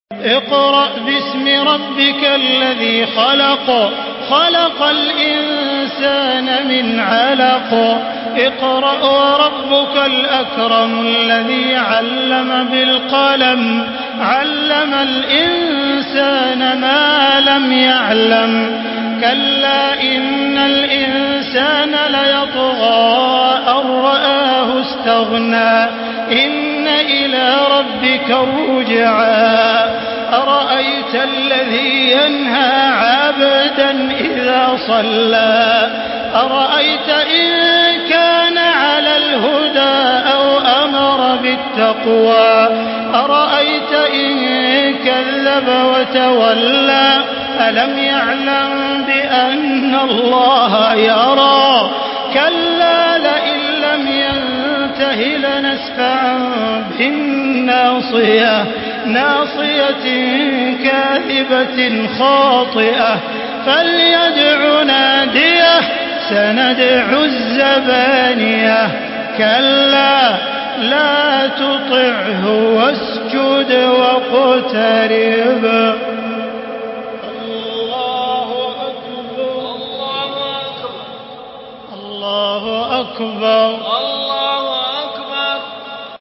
Surah Alak MP3 by Makkah Taraweeh 1435 in Hafs An Asim narration.
Murattal